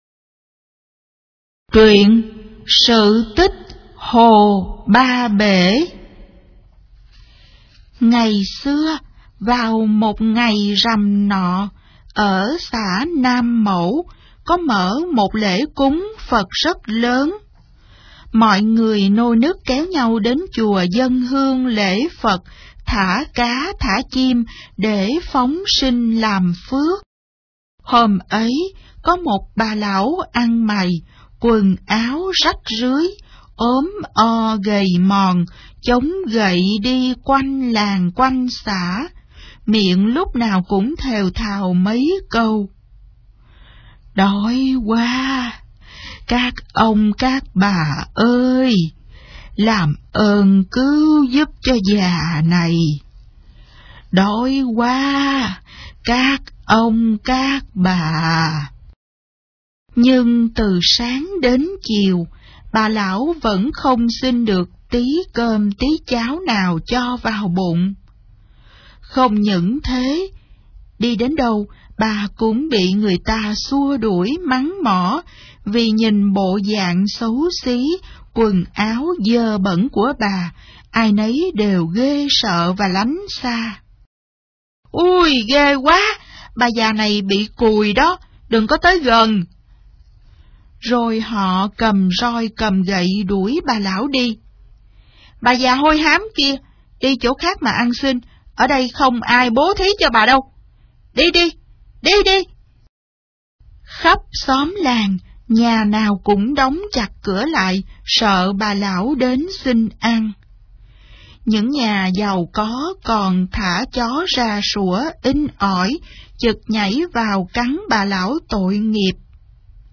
Sách nói | 25_TRUYEN_CO_TICH_VN25